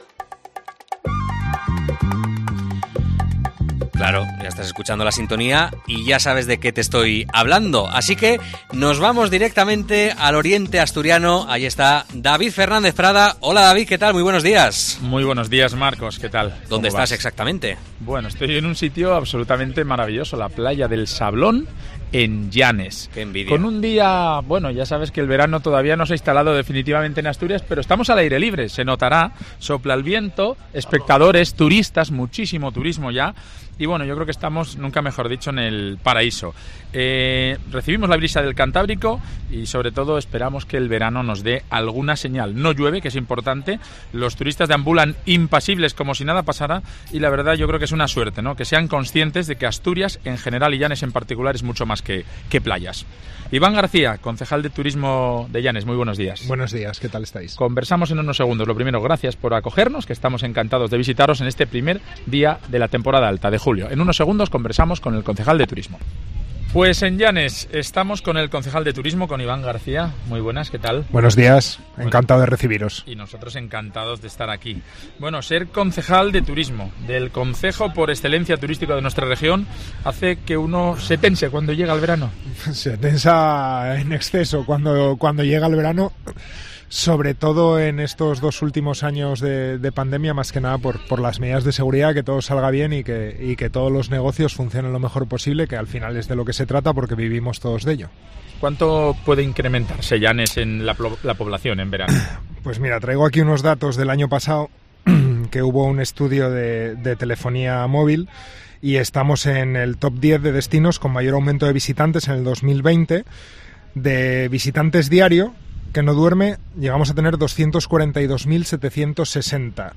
Seguimos haciendo turismo por el Principado de Asturias. El Rincón del Sibarita cambia los estudios centrales de Cope Asturias en Oviedo por las playas llaniscas. Desde la Playa de El Sablón conocemos la actualidad gastronómica asturiana de la mano de sus protagonistas.